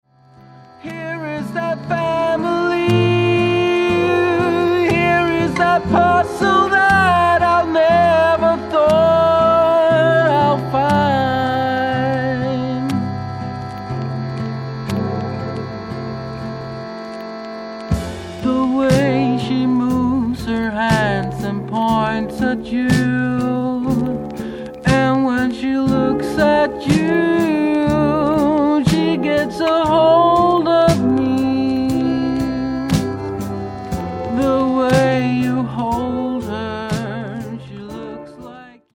大きなブリッジを描く名曲で、この大作の締めくくりにピッタリですﾉ。